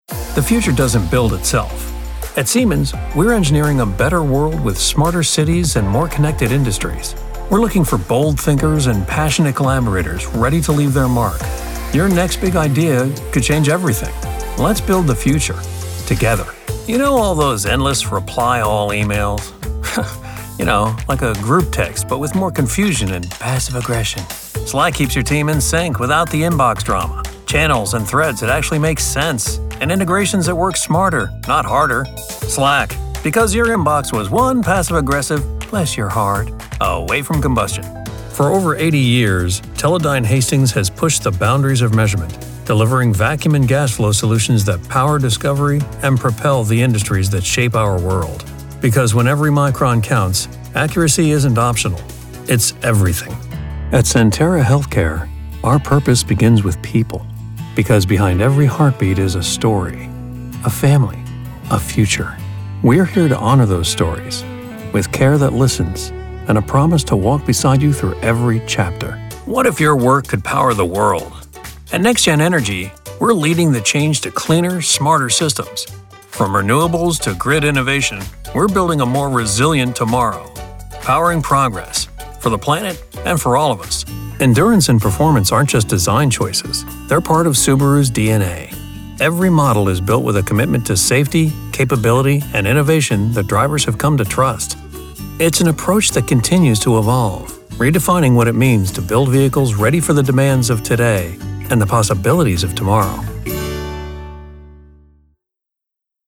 I'm a full time voice actor who has worked in multiple genres, delivering high quality audio from my professional home studio.
My accent is neutral American, although I can assume different regional American accents as well as some international ones.
Adult, Mature Adult